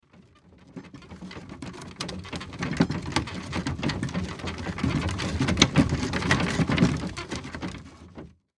Звуки скрипящего колеса
Скрип деревянного колеса при движении